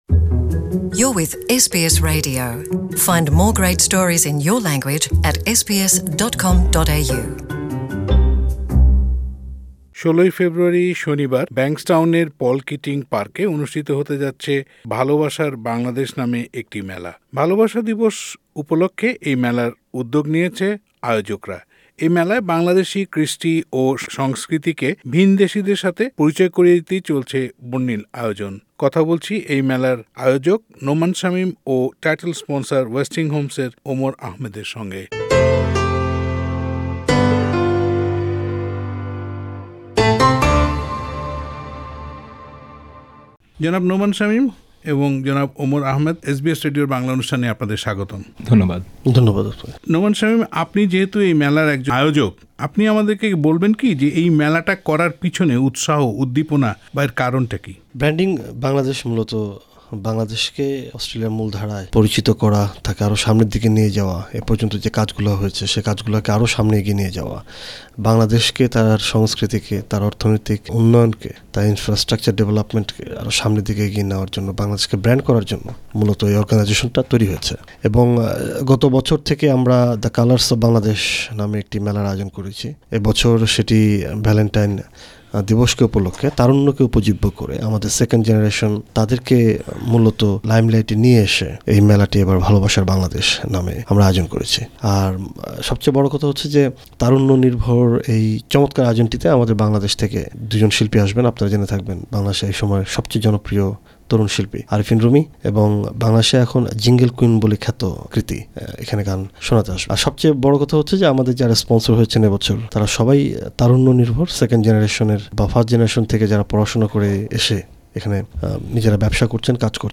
সাক্ষাৎকার বাংলায় শুনতে উপরের অডিও প্লেয়ারটিতে ক্লিক করুন।